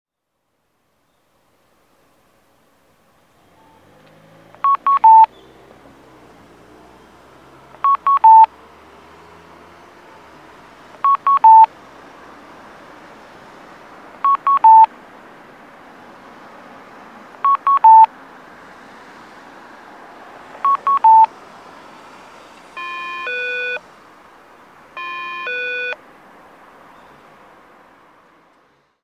楽老ハイツ南側(神奈川県横浜市瀬谷区)の音響信号を紹介しています。